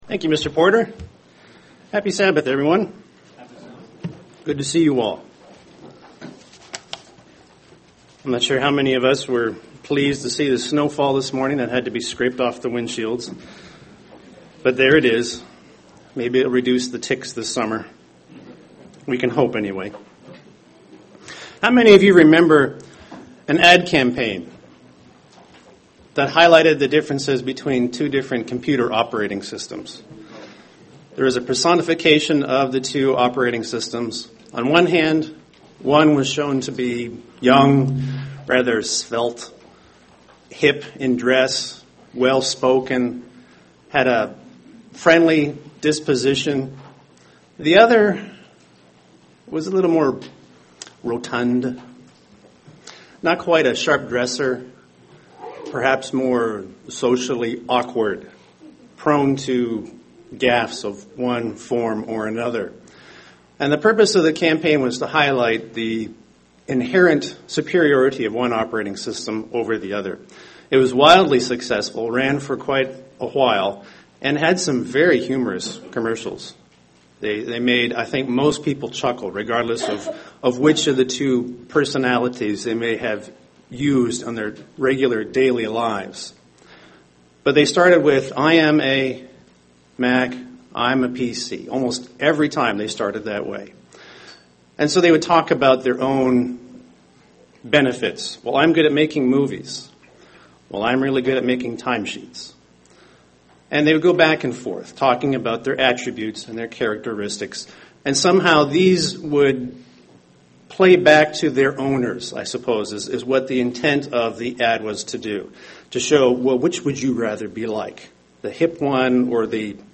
In this sermon we will review the seven "I AM" statements written in John.